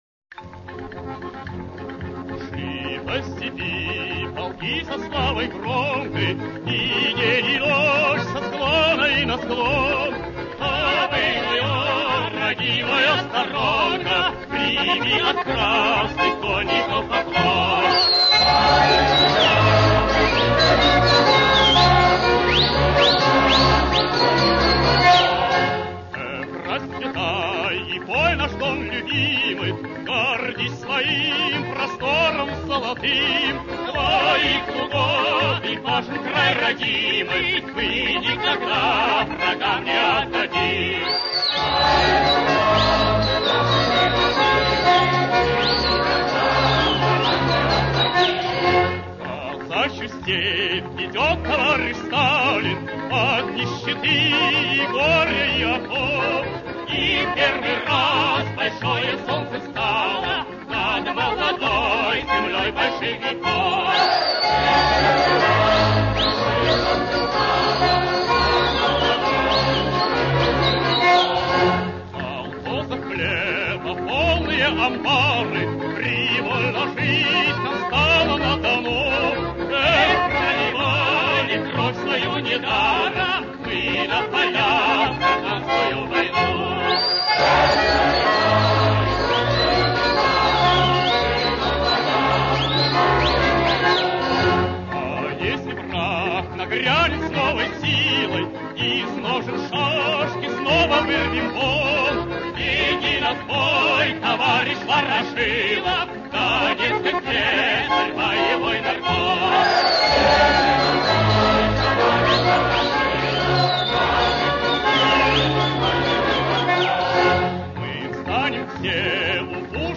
Довоенная песня донских казаков